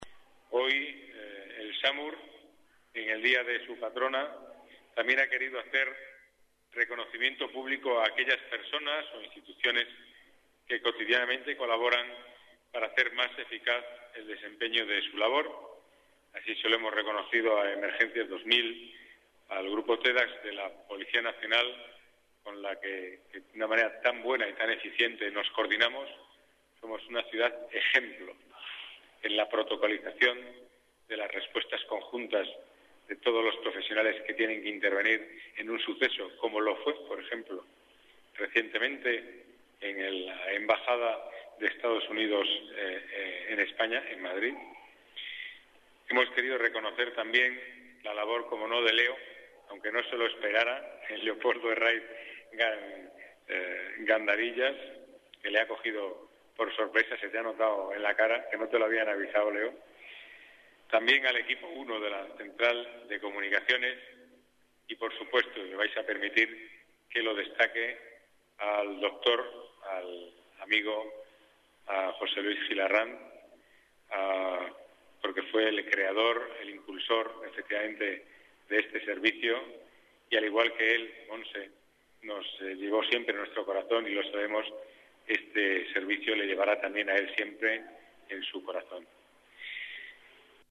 Nueva ventana:Declaraciones del delegado de Seguridad y Movilidad, Pedro Calvo